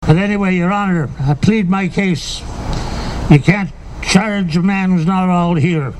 The Third Annual Arnprior Lions Jail and Bail had more comedy and drama than ever before, as the fundraiser nestled into the Giant Tiger Parking Lot Saturday.